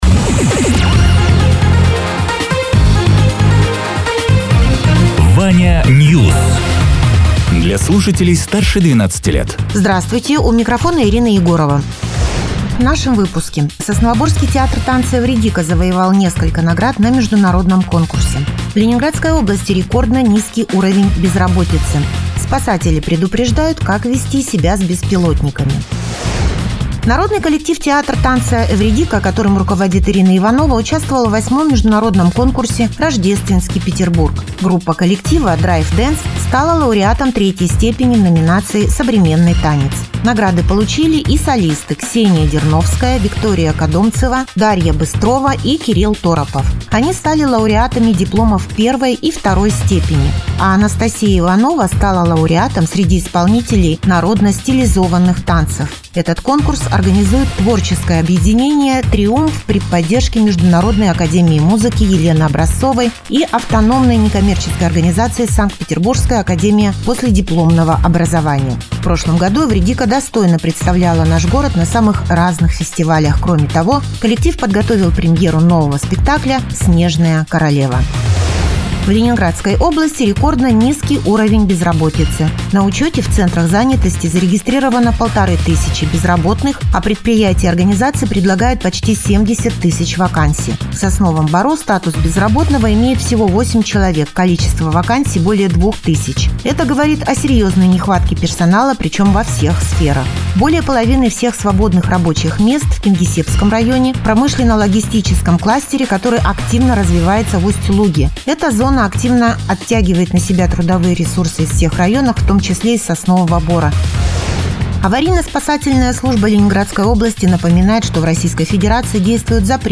Радио ТЕРА 14.01.2025_08.00_Новости_Соснового_Бора